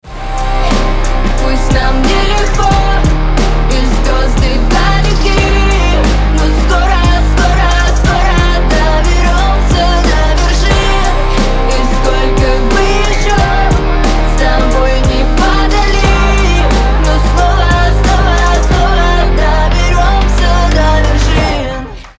• Качество: 320, Stereo
гитара
красивый женский голос